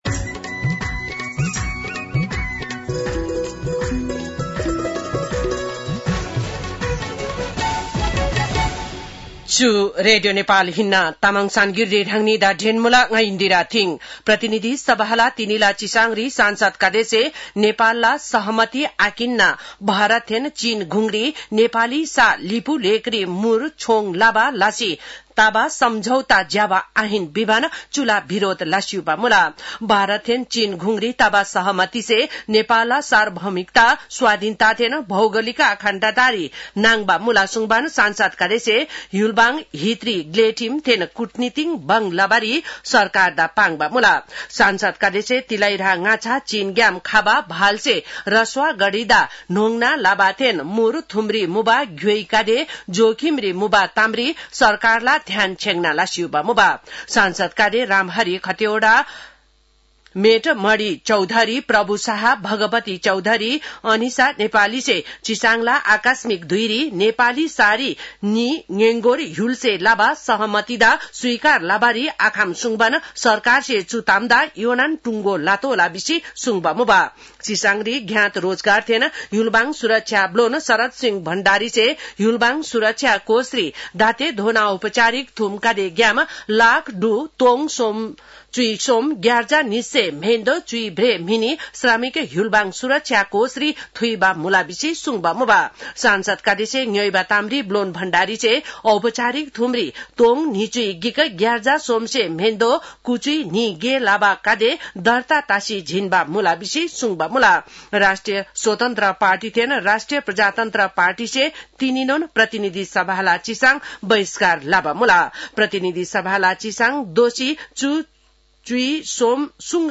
तामाङ भाषाको समाचार : ६ भदौ , २०८२